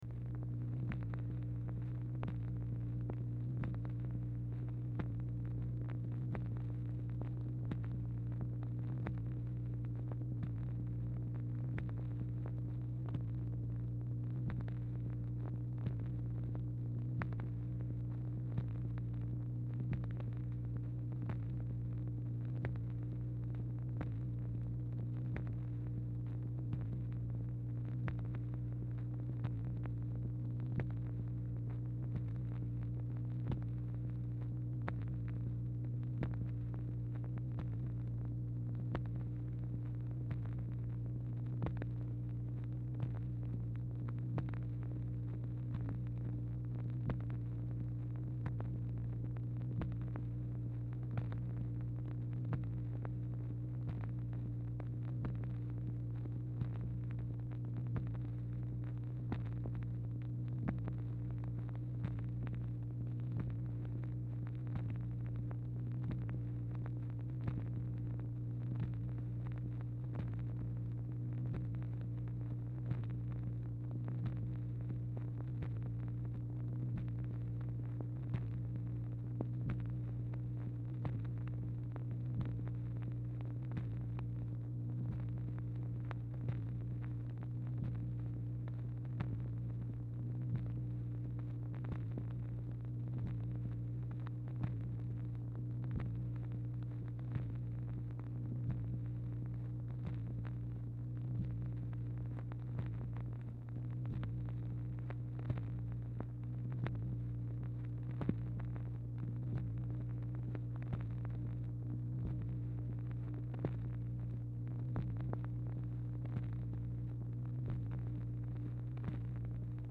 Telephone conversation # 748, sound recording, MACHINE NOISE, 12/25/1963, time unknown | Discover LBJ
Format Dictation belt
Location Of Speaker 1 LBJ Ranch, near Stonewall, Texas
Speaker 2 MACHINE NOISE